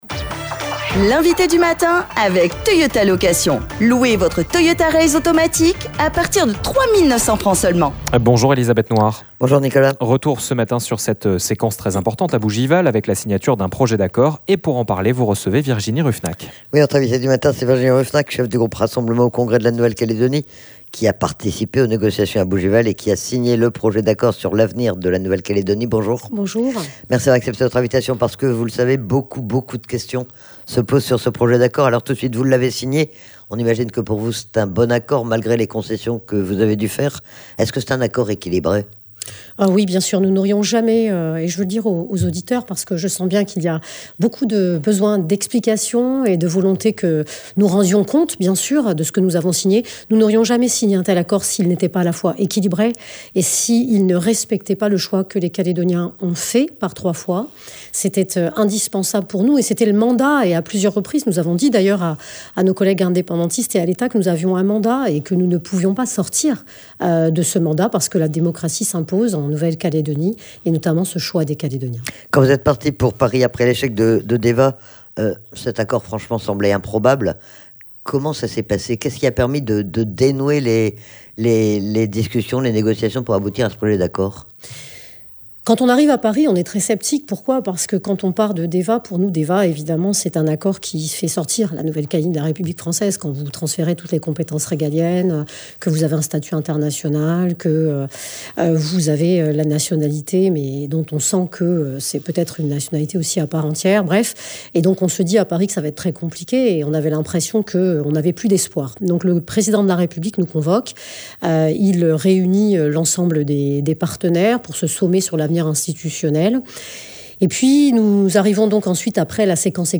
L'INVITEE DU MATIN : VIRGINIE RUFFENACH
Que contient vraiment le projet d'accord sur l'avenir de la Nouvelle-Calédonie signé à Bougival ? Nous en avons parlé avec Virginie Ruffenach, la chef du groupe Rassemblement au congrès.